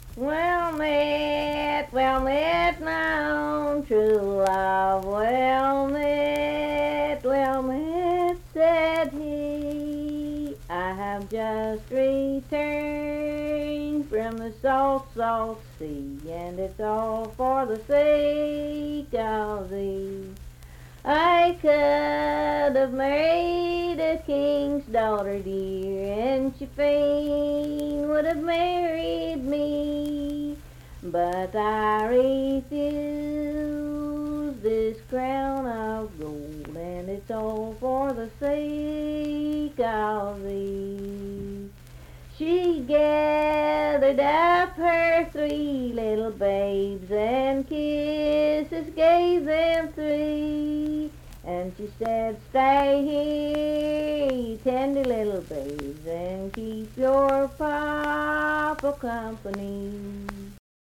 Unaccompanied vocal music
Verse-refrain 3(4).
Voice (sung)
Nicholas County (W. Va.), Richwood (W. Va.)